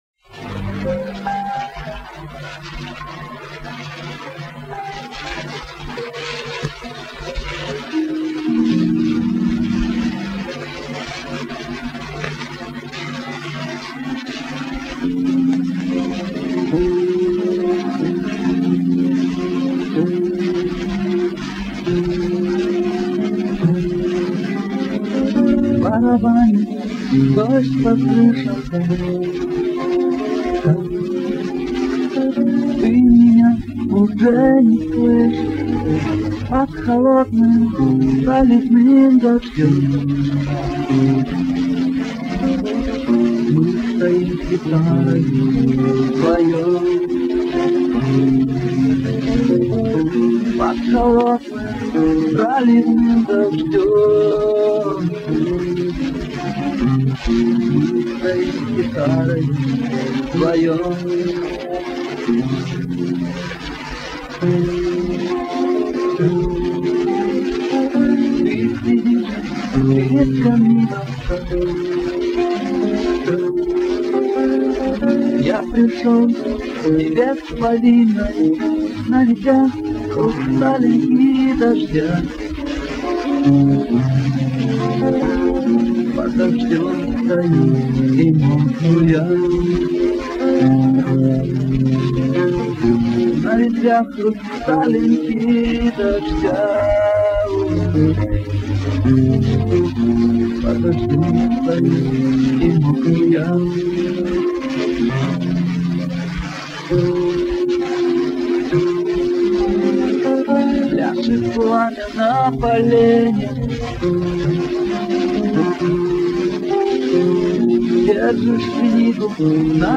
-У меня есть записи с радио-хулиганского эфира, 90-x годов.
Записи очень плохого качества..
Оцифрованные версии (моно) именно ЭТОЙ песни, именно ЭТОГО исполнителя - ниже.. Оригинал и фильтрованная (насколько смог разобраться и сделать) версия.
moya-lyubov-(s-filtrami).mp3